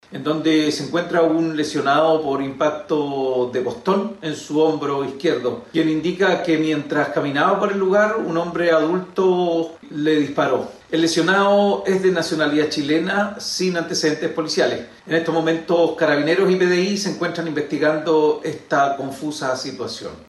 En ese contexto, el delegado presidencial provincial de Marga Marga, Fidel Cueto, informó que la policía civil se sumó a las diligencias para determinar la dinámica de los hechos.